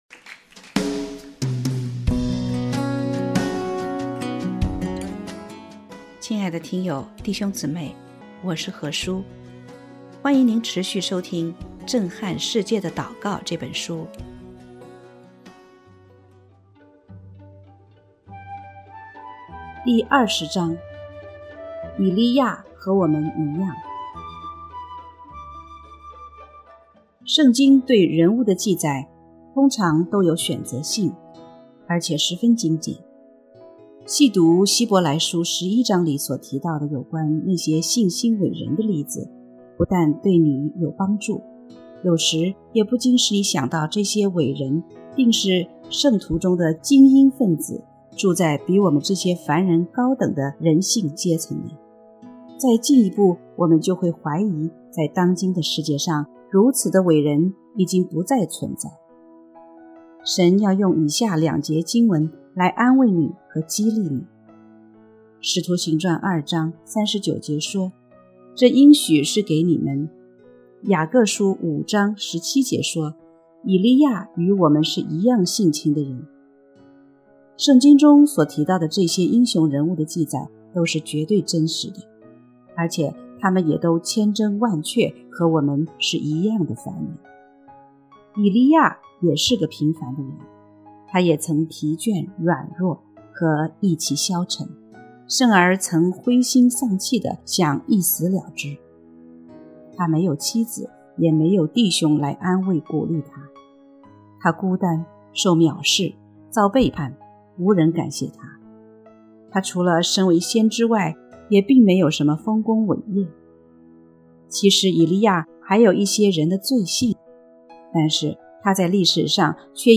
欢迎你持续收听《震撼世界的祷告》这本书。